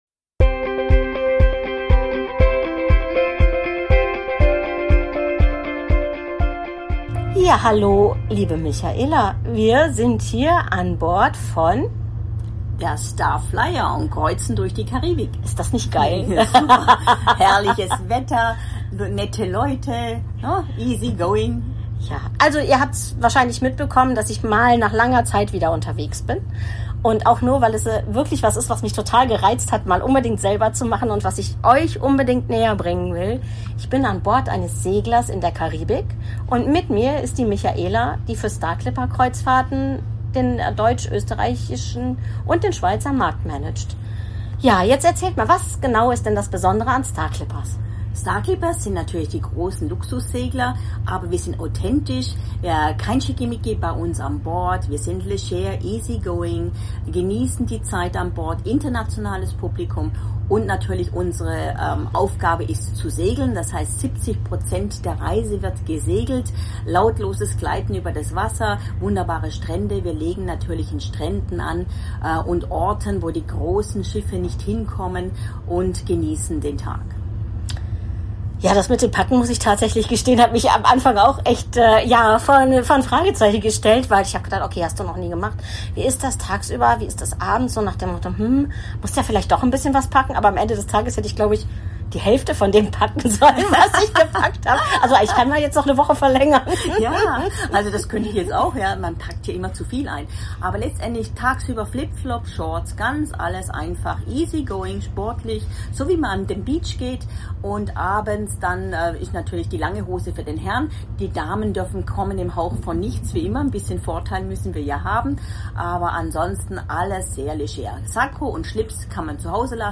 Beschreibung vor 2 Monaten Karibischer Traum – An Bord der Star Flyer Kommt mit uns auf eine Reise über türkisfarbene Horizonte und erlebt das besondere Gefühl klassischer Segelromantik. In dieser Folge teilen wir unsere persönlichen Eindrücke, Stimmungen und Momente direkt von Bord. P.S. Verzeiht uns die Tonqualität – auf See ist es manchmal etwas ruckelig.